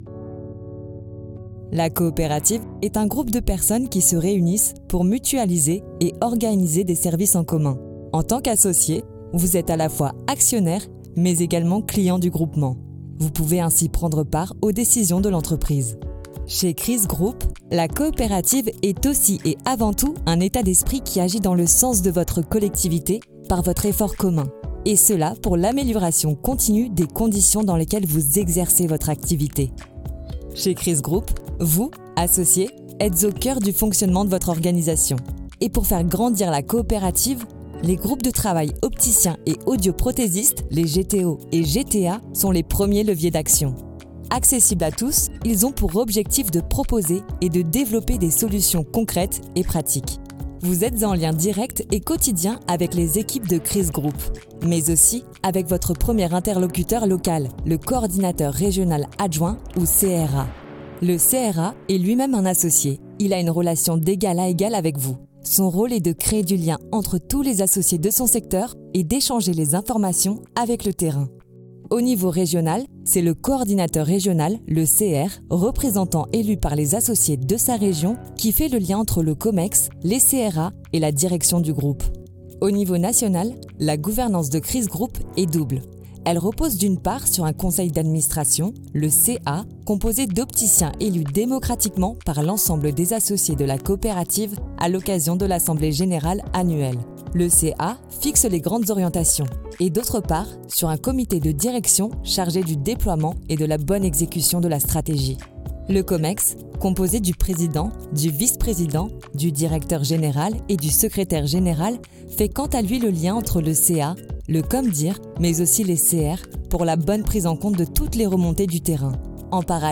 Natural, Llamativo, Versátil, Seguro, Cálida
E-learning